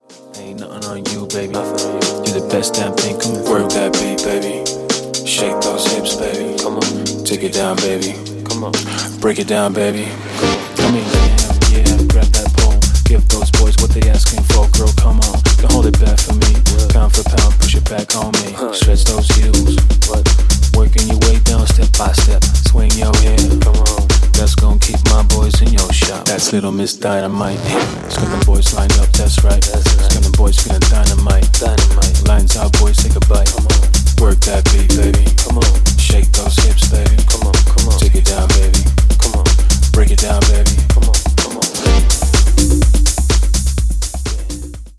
Uptempo party starter
Kind of hip house V2.0
House